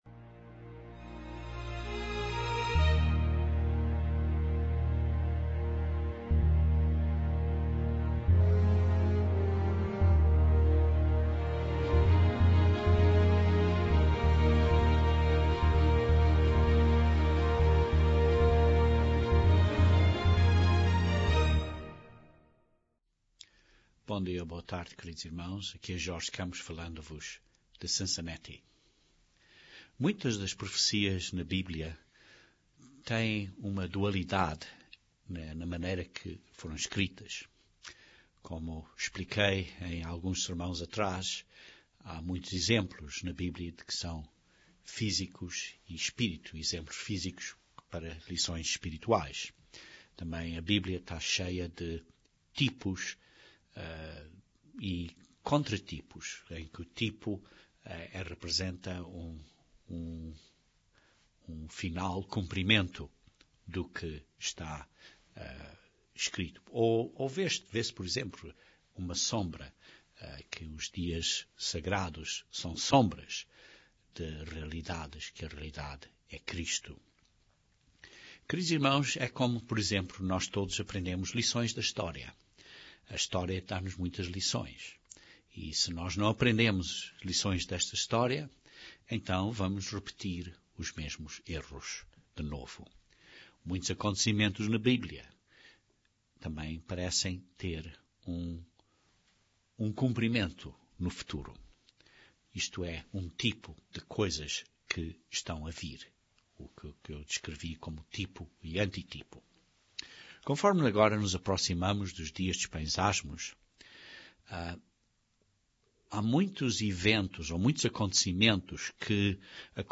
Este sermão descreve várias analogias entre Moisés e o Profeta profetizado.